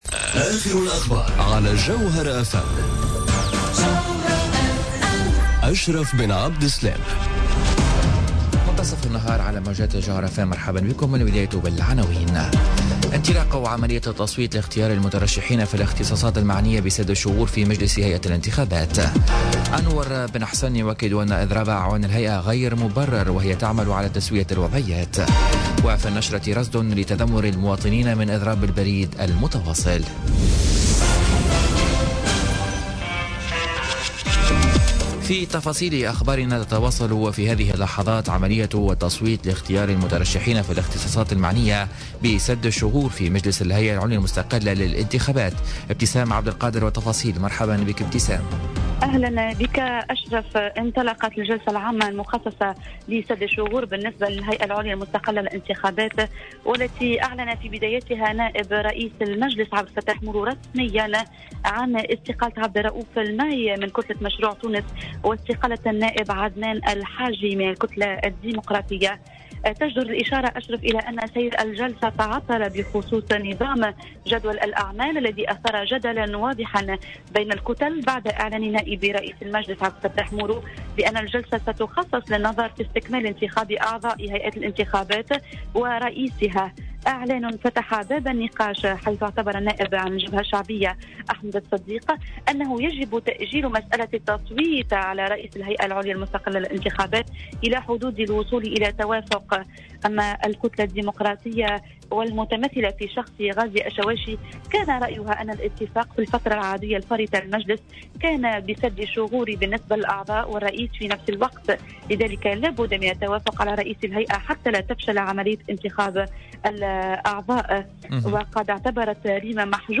نشرة أخبار منتصف النهار ليوم الإربعاء 20 سبتمبر 2017